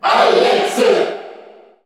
Category:Crowd cheers (SSBU) You cannot overwrite this file.
Alex_Cheer_Korean_SSBU.ogg.mp3